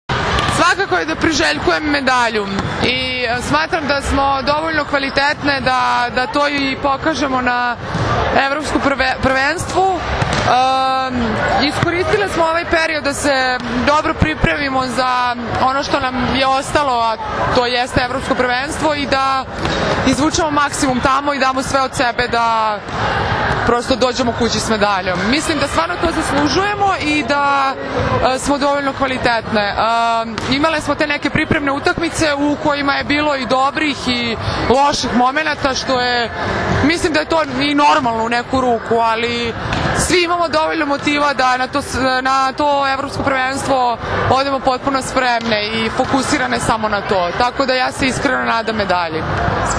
IZJAVA BOJANE ŽIVKOVIĆ